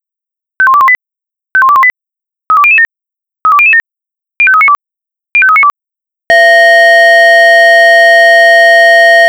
Jetzt einfach nur gut zuhören und die Lautsprecher vorher etwas leiser drehen.
Sonst könnte es passieren, daß sich eure Nachbarn beschweren, die Lautsprecher oder euer Gehör schaden nehmen.
piep_piep_piep.wav